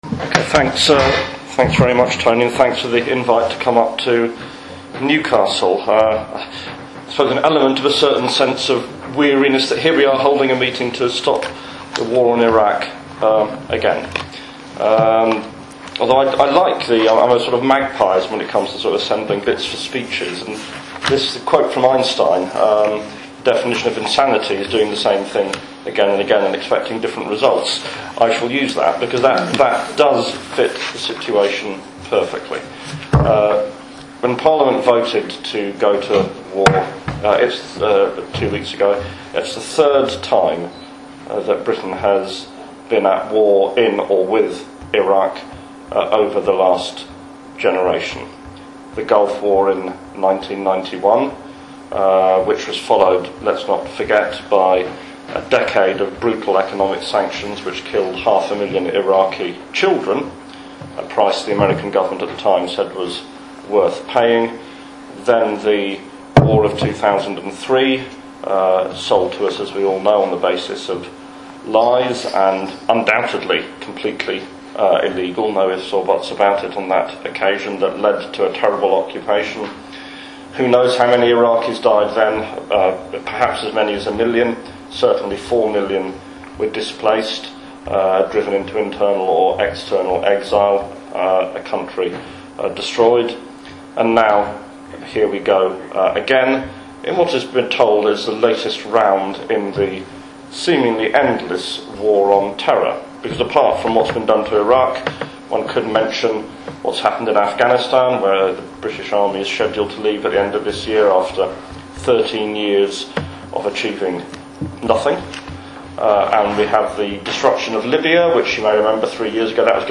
Newcastle Stop the War Coalition and the Newcastle University's Power/Space/Politics research cluster organised a very important public meeting in Newcastle on Monday May 12th, 2014 on the New Scramble for Africa.